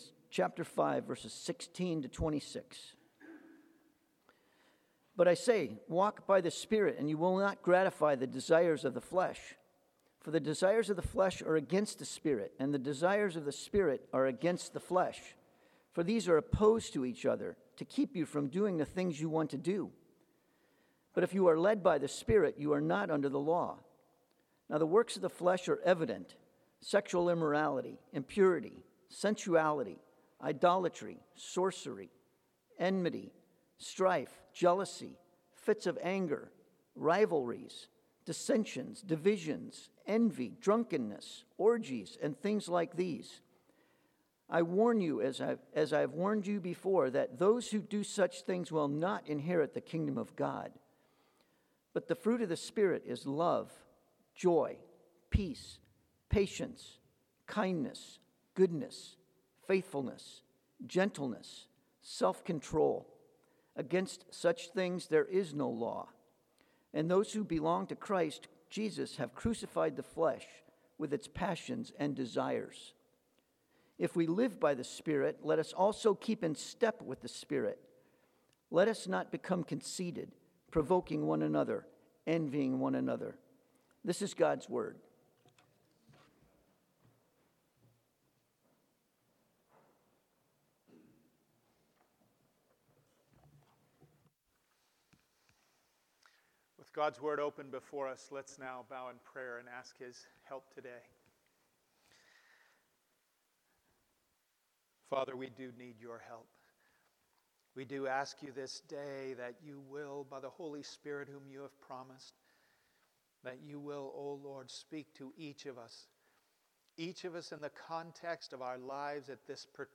Passage: Galatians 5:19-26 Sermon